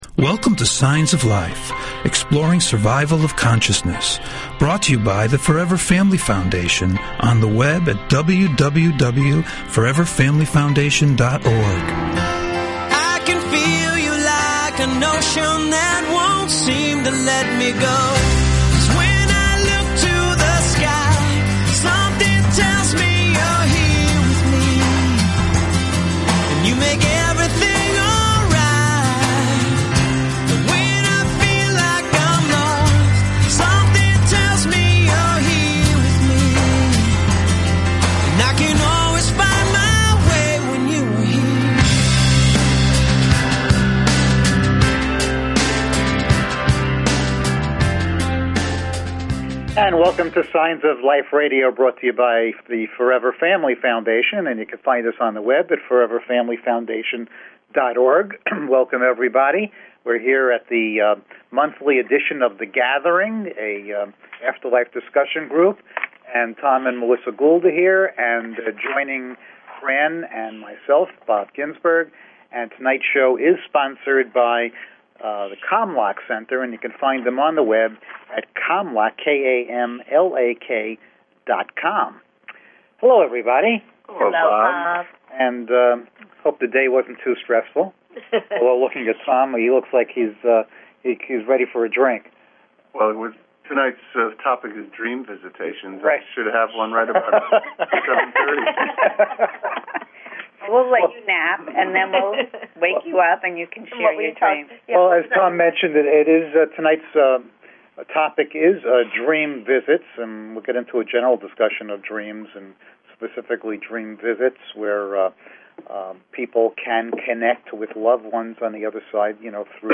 Talk Show Episode, Audio Podcast, Signs_of_Life and Courtesy of BBS Radio on , show guests , about , categorized as
SHORT DESCRIPTION - The Gathering afterlife discussion panel - Topic: Dream Visits
Call In or just listen to top Scientists, Mediums, and Researchers discuss their personal work in the field and answer your most perplexing questions.